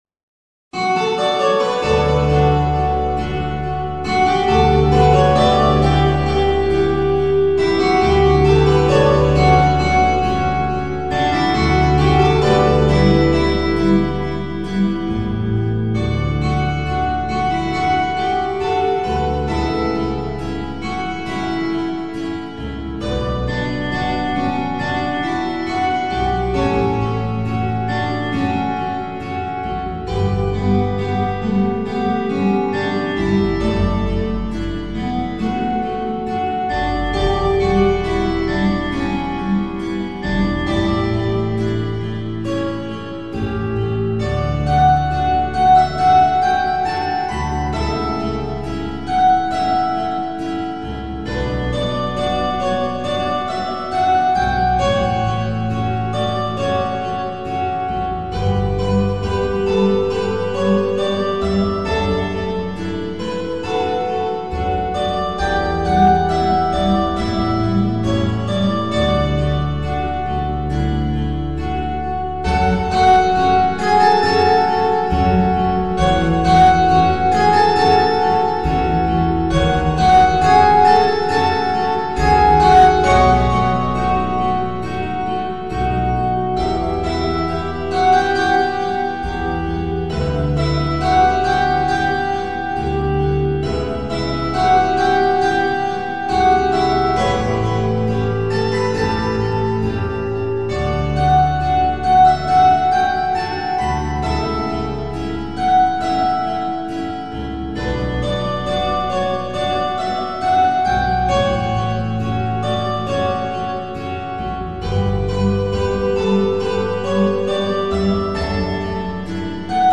Besetzung: Zupforchester